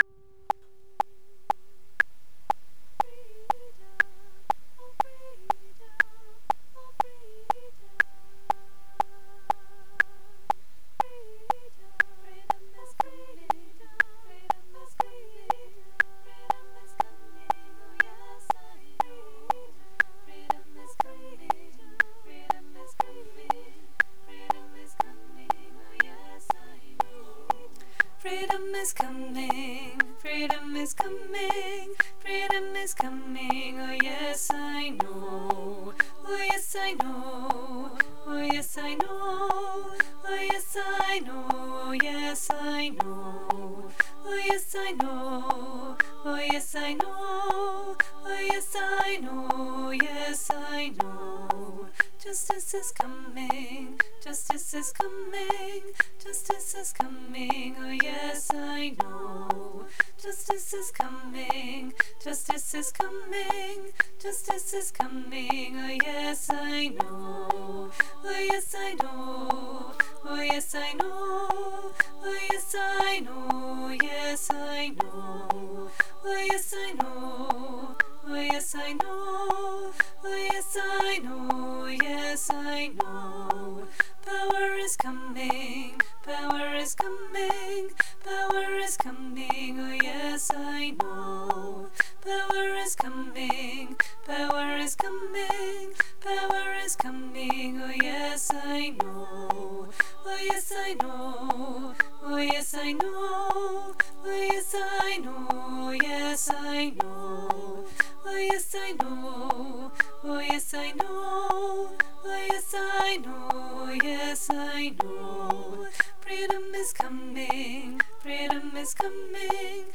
Freedom is Coming for SCF19 BASS
Freedom-is-Coming-for-SCF19-BASS.mp3